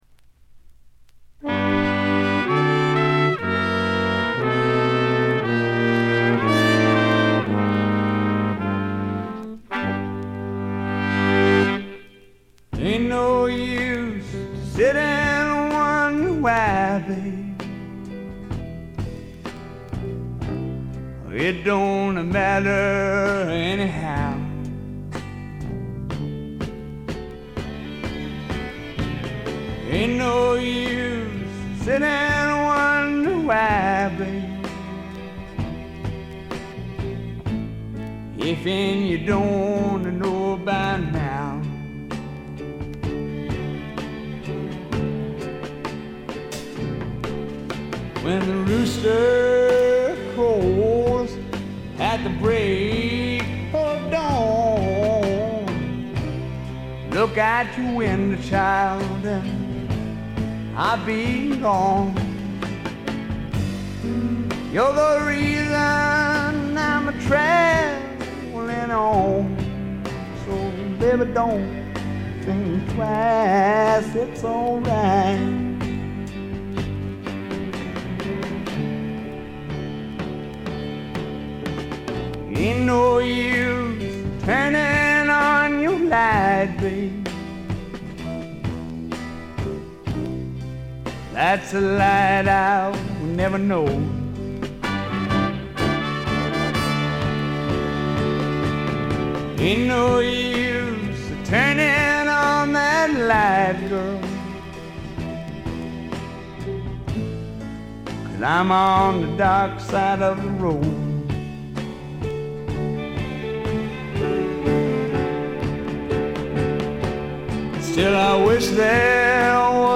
60年代的なポップな味付けを施しながらも、ねばねばなヴォーカルがスワンプど真ん中の直球勝負で決めてくれます。
試聴曲は現品からの取り込み音源です。